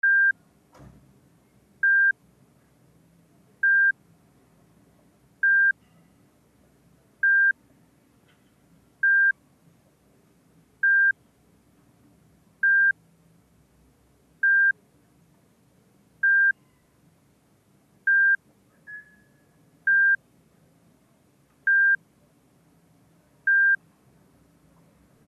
AMBIENTE DE HOSPITAL
Ambient sound effects
ambiente_de_hospital.mp3